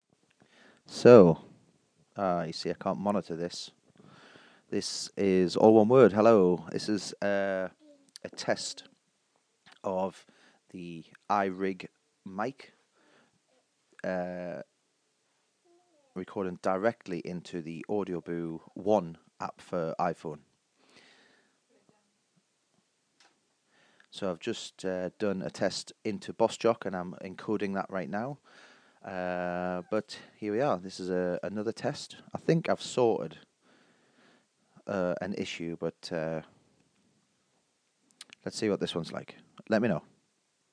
iRig Mic test direct into Audioboo app